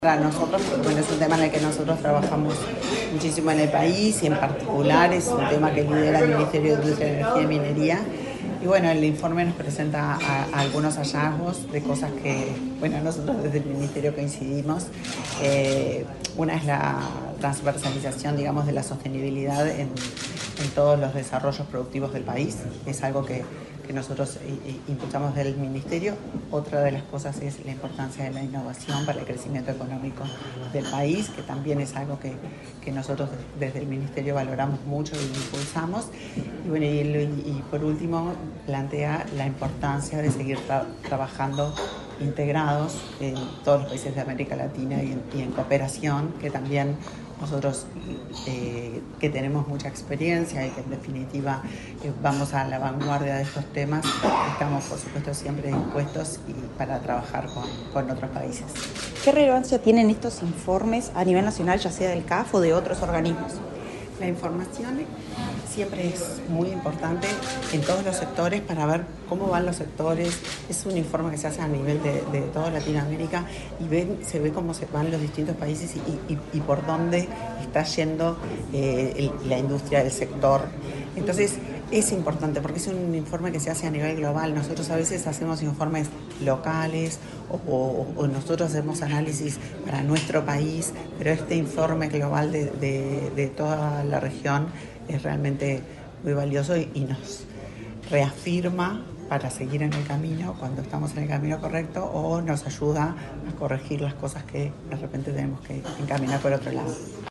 Declaraciones de la ministra de Industria, Elisa Facio